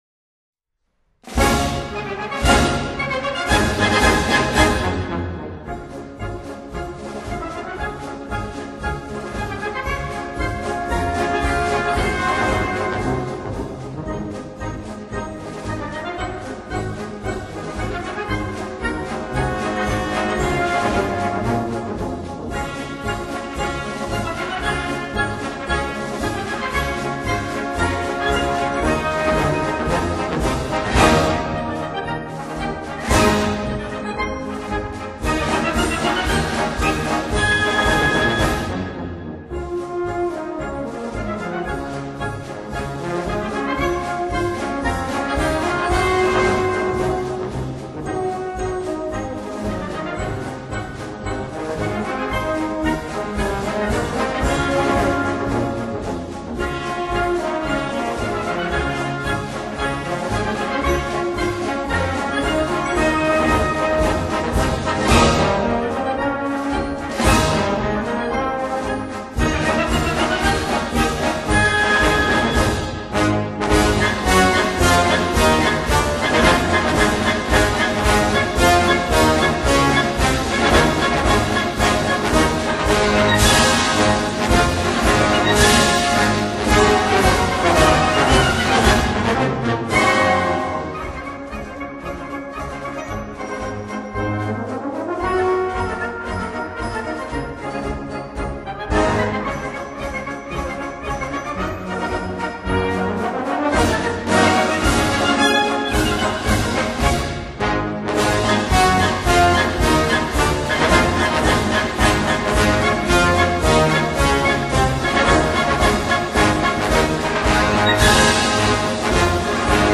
questa è un esempio di marcetta), non con altra musica più o meno tale e si compongono a compendio di un anno di vita e d’amicizia tra gli squadristi, non come si sarebbe voluto per il vostro articolista che lo scorso ventiquattro dicembre si è visto ‘incontrare’ da chi, non presente in paese durante la maggior parte dell’anno, ha fatto finta di non sapere che gente che abita a cinquanta metri dalla casa di chi sta dando tedio, non rivolgendo a questo la parola nemmeno per scherzo, poi non può pretendere che lo scrivente, in quanto fisarmonica e cantore, possa accettare di comparire con tal insieme in ossequio al ‘volemose bene in nome del capodanno?.